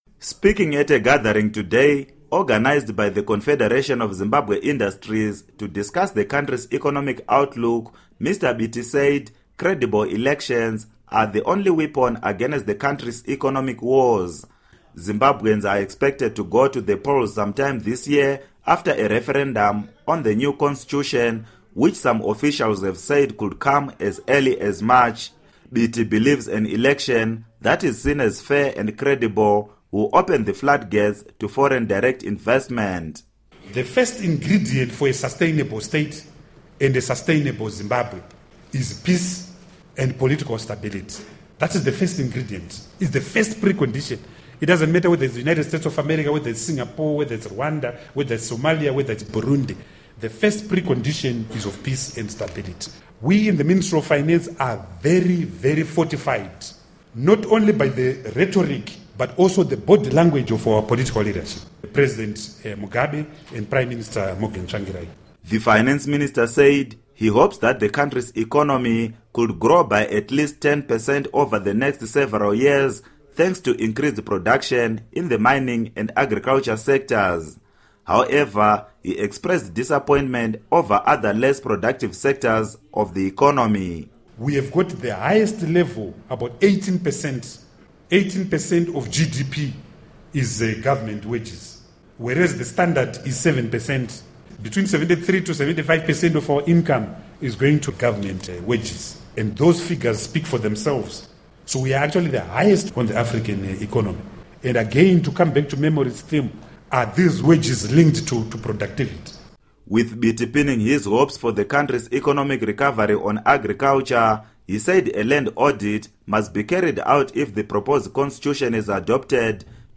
Speaking at a gathering organized by the Confederation of Zimbabwe Industries to discuss the country’s economic outlook, Mr. Biti said credible elections are the only weapon against the country's economic woes.